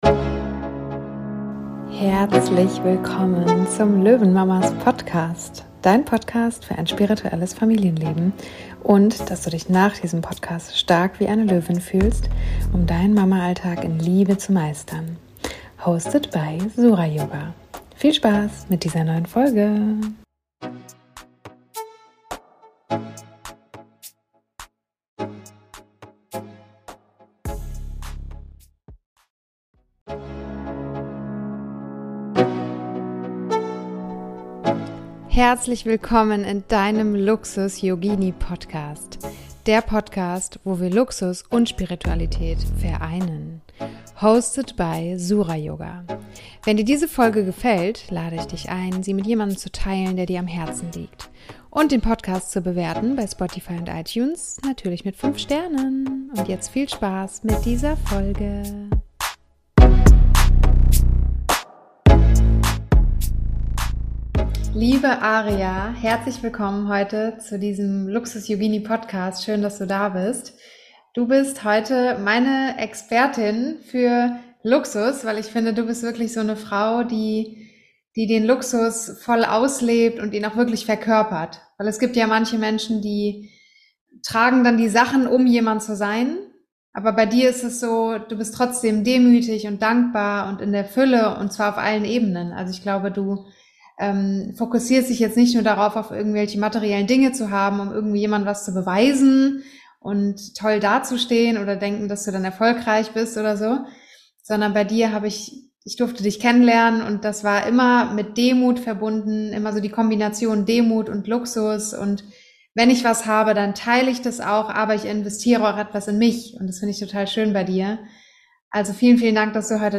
#3 Chanel vs. Jutebeutel Interview